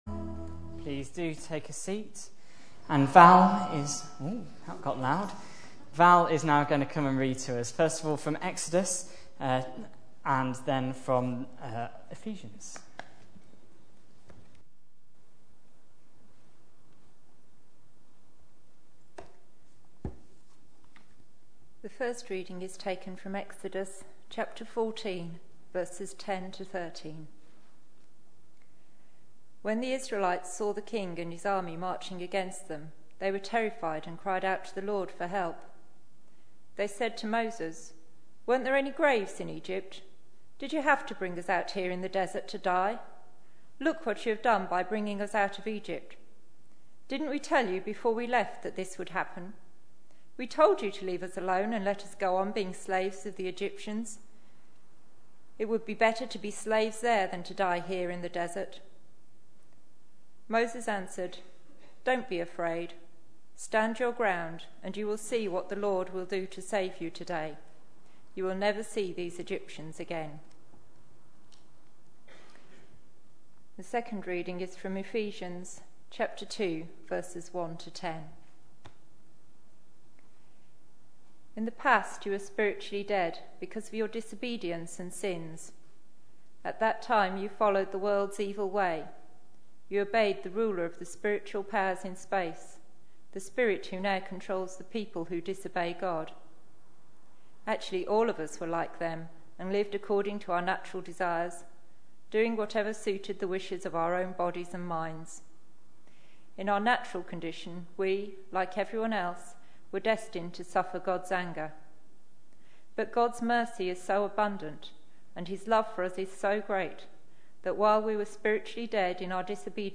A sermon preached on 10th June, 2012, as part of our A Purple Chapter series.